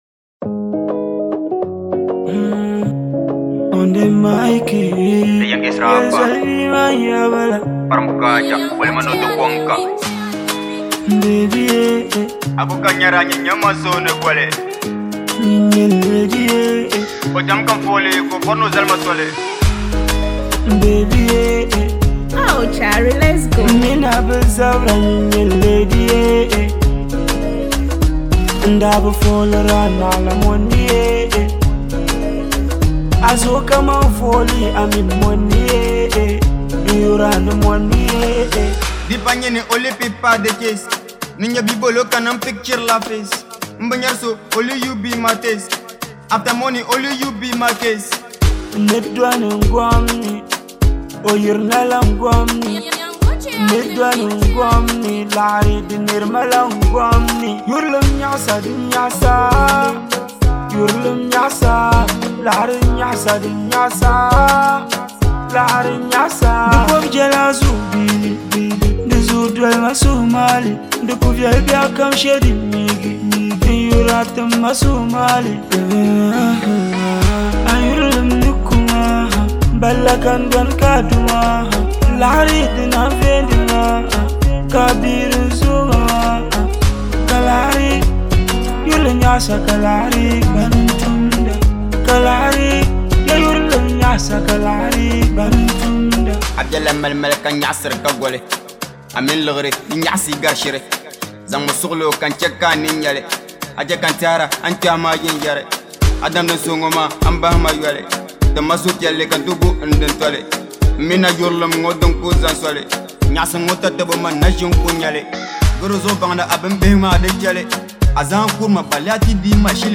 With its catchy flow and cultural depth